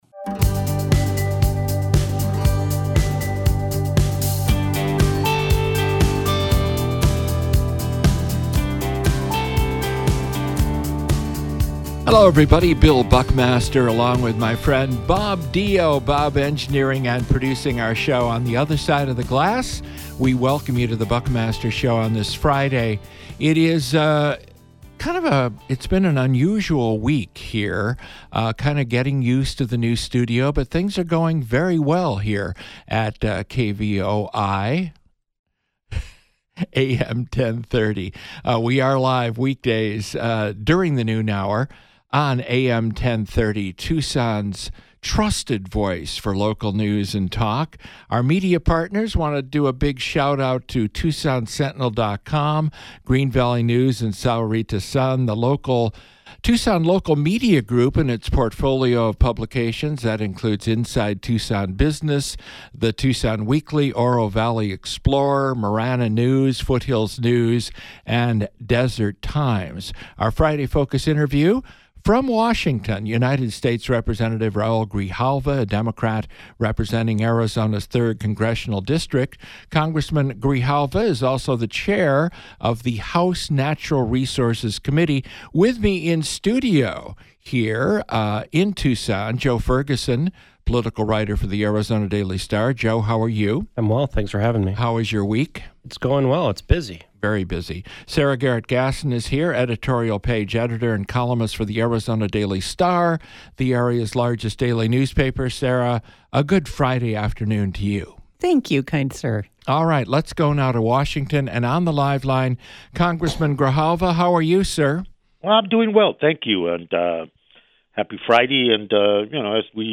Our Friday Focus interview with United States Representative Raul Grijalva (D-District 3).